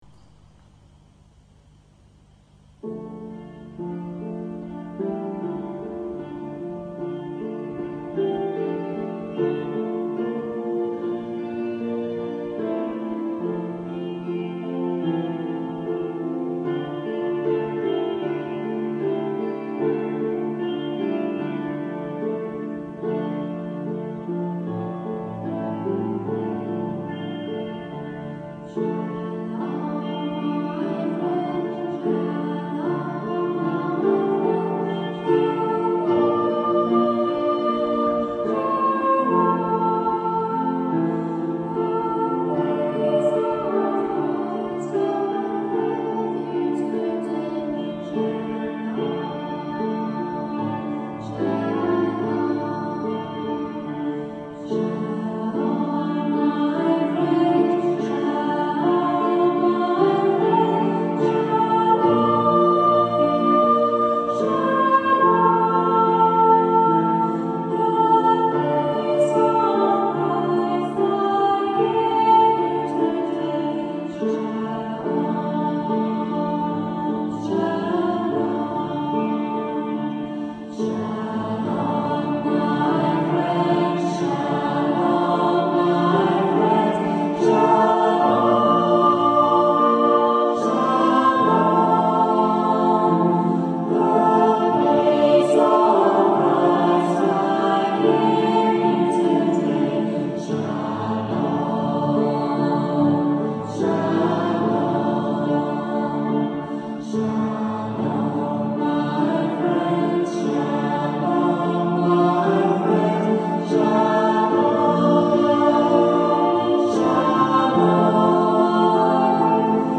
Recorded at rehearsal 17th March 1999 in digital stereo on minidisc.